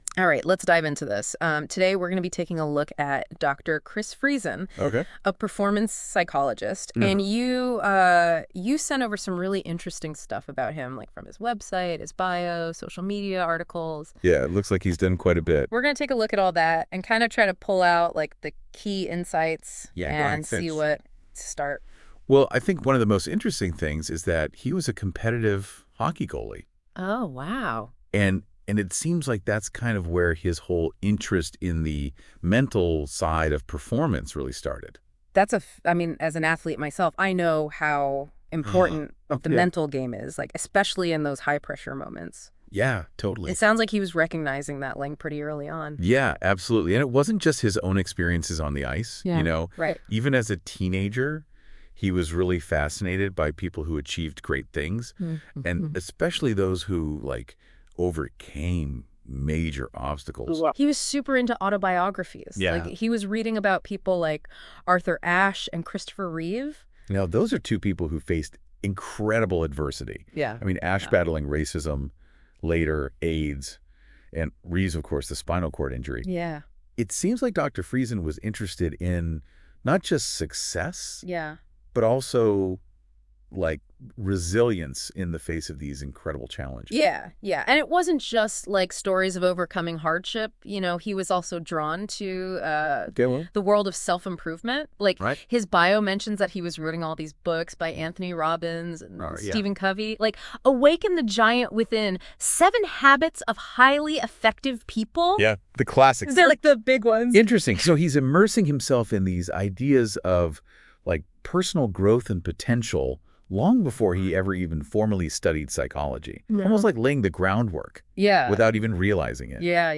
AN AI GENERATED DISCUSSION